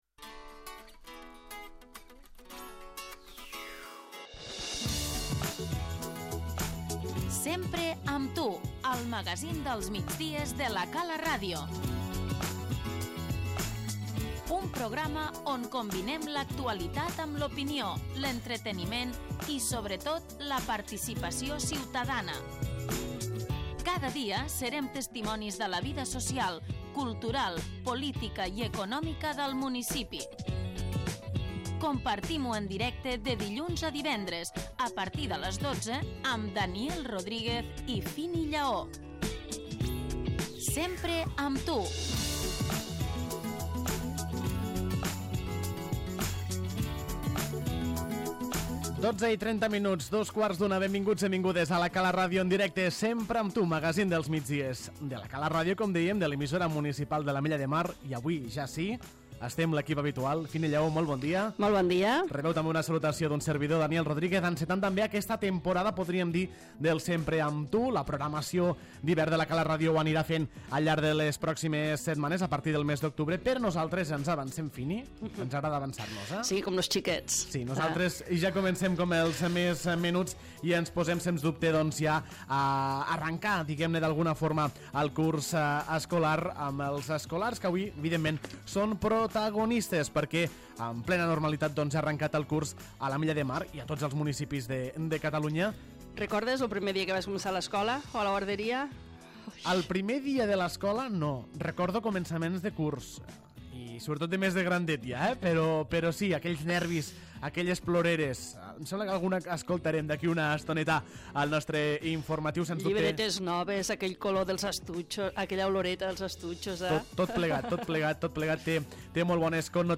L'ENTREVISTA Al llarg d'aquesta setmana recorrerem els diferents centres educatius de l'Ametlla de Mar per conèixer l'inici del curs escolar.